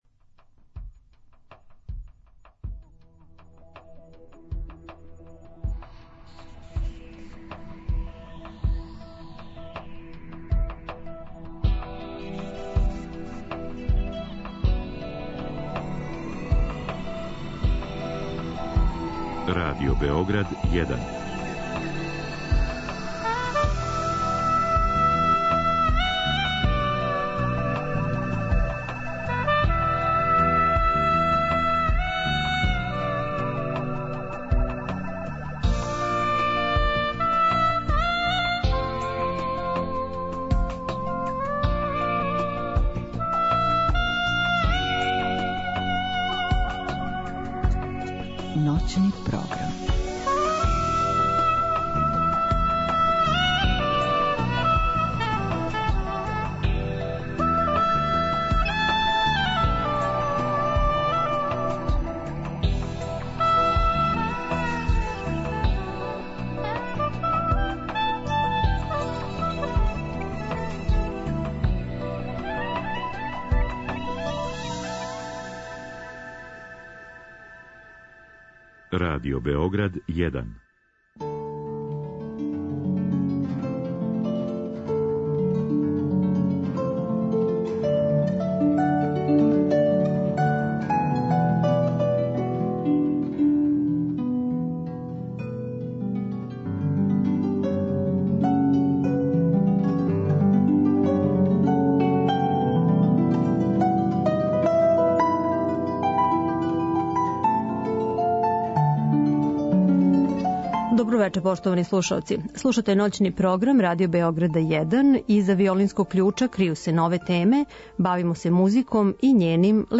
са слушаоцима ће бити водитељи и гости у студију